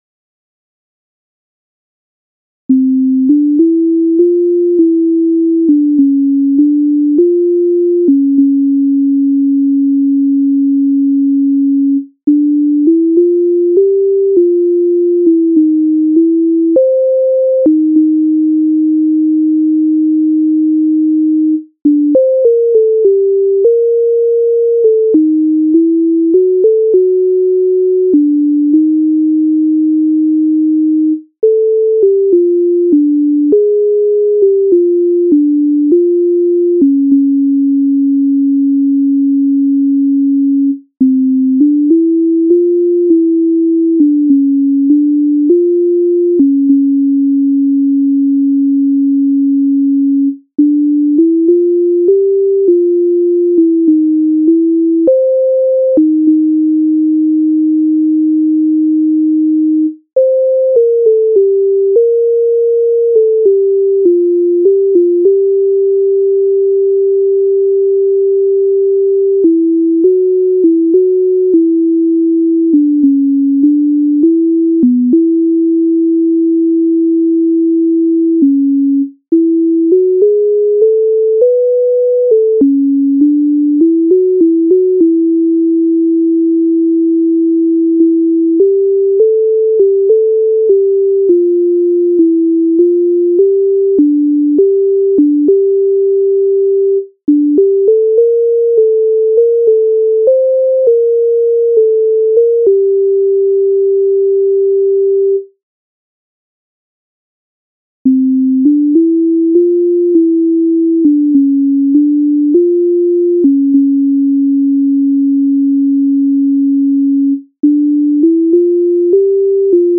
MIDI файл завантажено в тональності F-dur